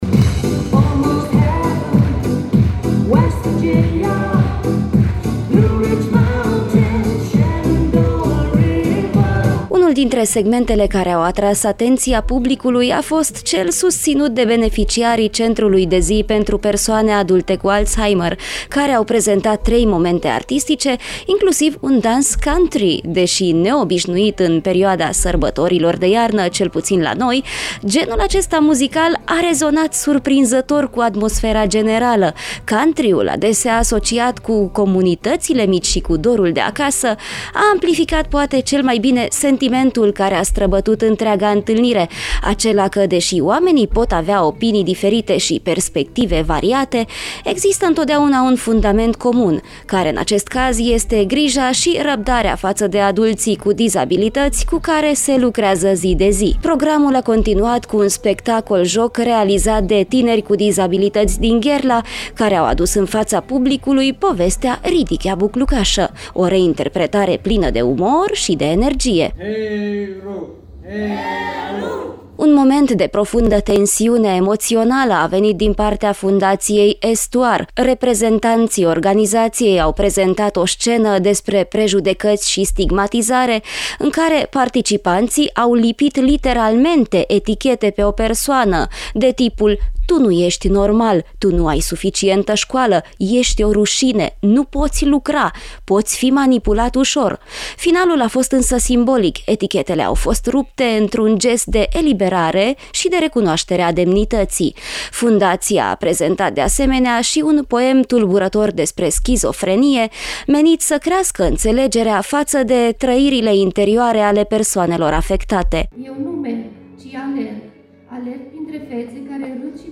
Ziua Persoanelor cu Dizabilităţi a fost sărbătorită în sala Radio Cluj | AUDIO
Eveniment dedicat Zilei Internaționale a Persoanelor cu Dizabilități.